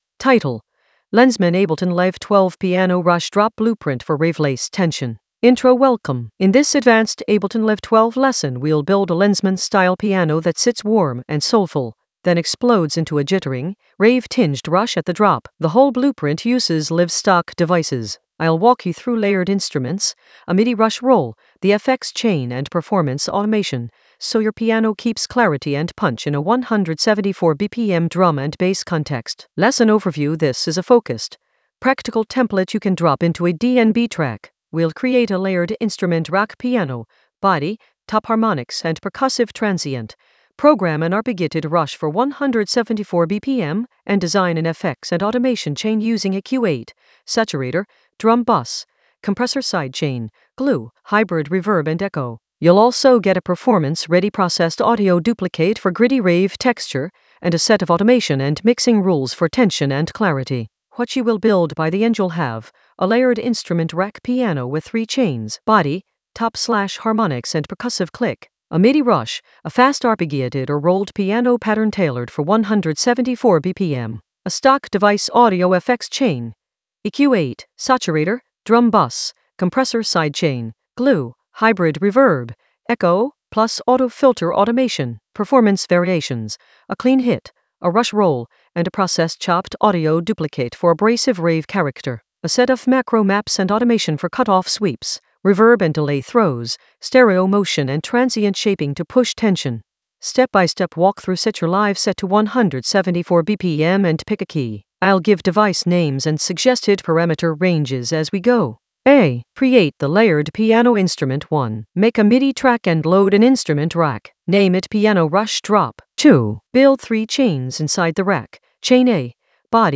Narrated lesson audio
The voice track includes the tutorial plus extra teacher commentary.
An AI-generated advanced Ableton lesson focused on Lenzman Ableton Live 12 piano rush drop blueprint for rave-laced tension in the Sound Design area of drum and bass production.